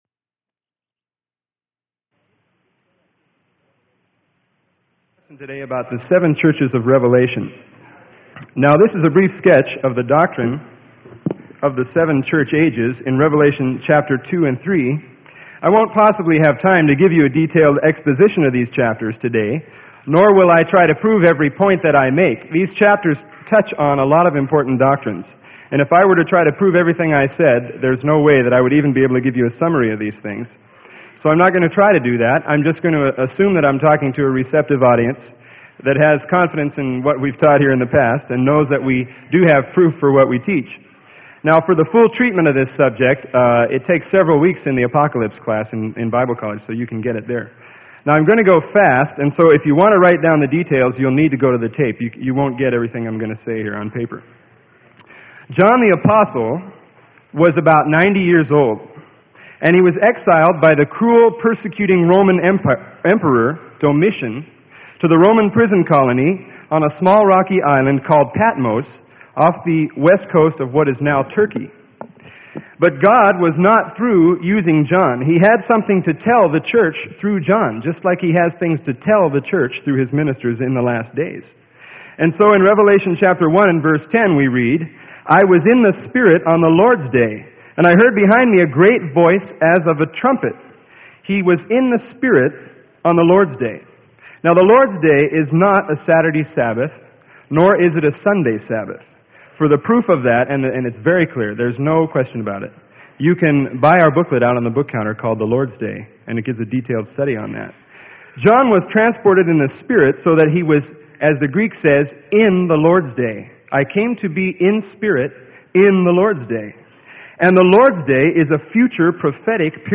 Sermon: SEVEN (7) CHURCHES OF REVELATION - Freely Given Online Library